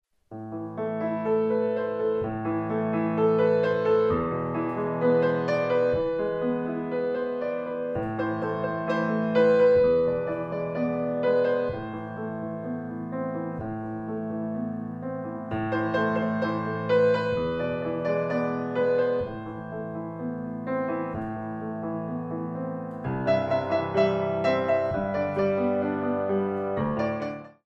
By Pianist & Ballet Accompanist
39 Popular & Traditional songs for Ballet classes
Piano selections include:
Frappe 4/4